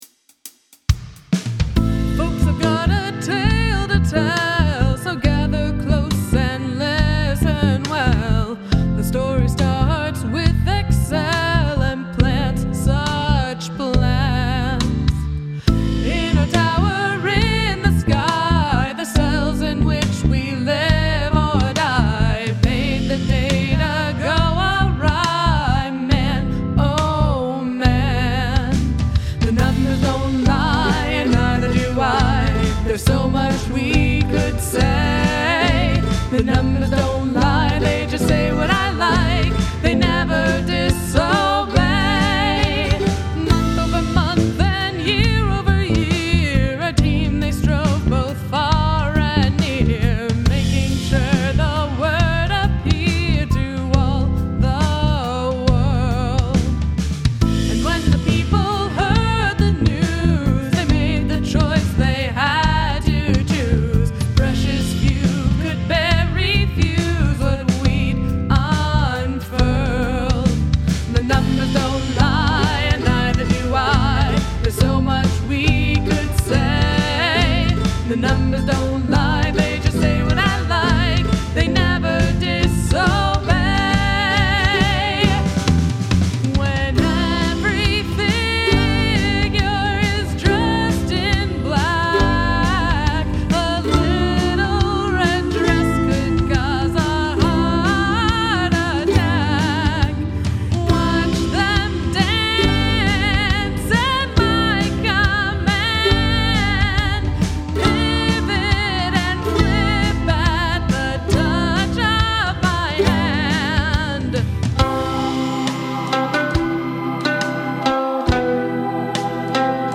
Use the Royal Road chord progression